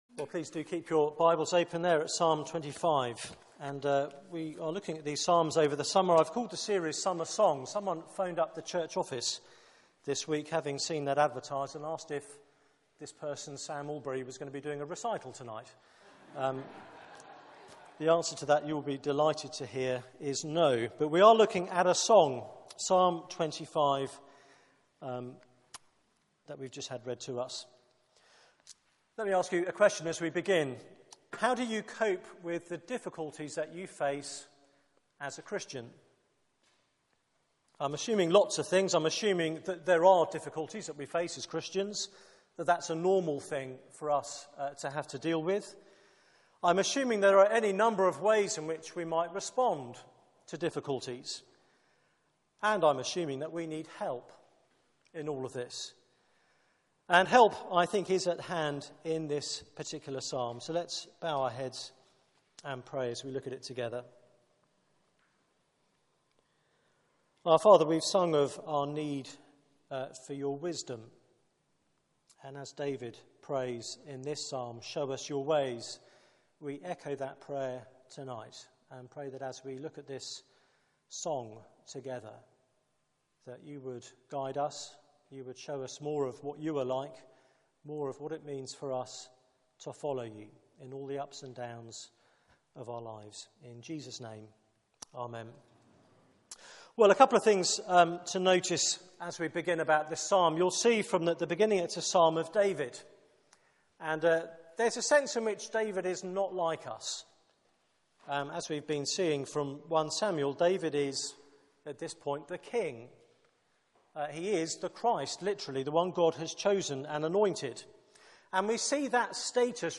Media for 6:30pm Service on Sun 03rd Aug 2014
Theme: 'Show me your ways' Sermon